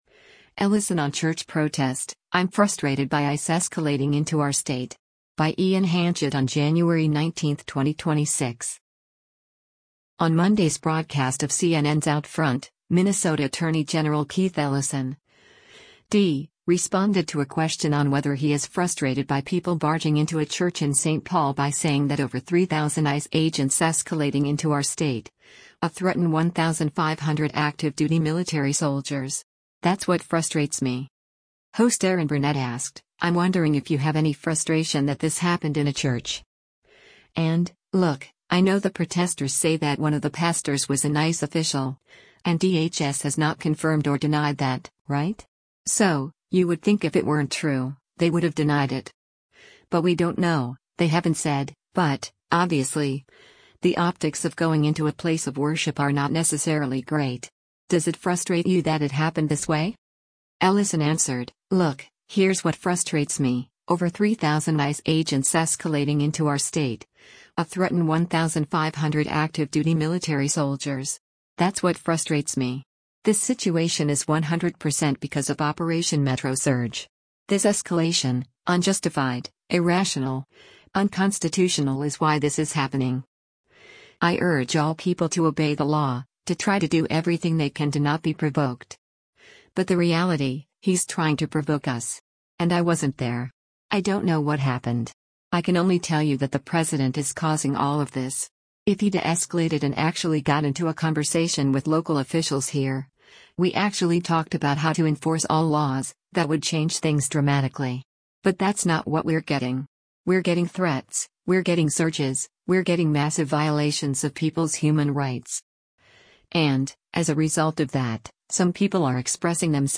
On Monday’s broadcast of CNN’s “OutFront,” Minnesota Attorney General Keith Ellison (D) responded to a question on whether he is frustrated by people barging into a church in St. Paul by saying that “Over 3,000 ICE agents escalating into our state, a threatened 1,500 active-duty military soldiers. That’s what frustrates me.”